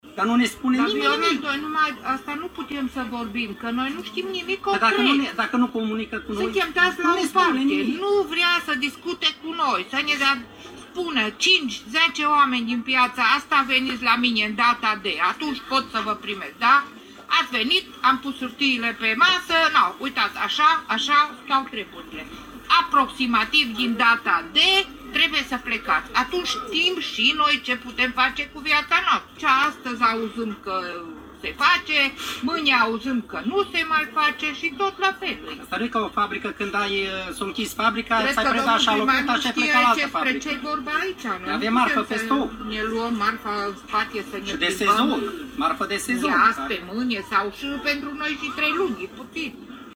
Comercianţii din Piaţă sunt însă nemulțumiți şi susţin că nu ştiu nimic concret legat de mutarea lor: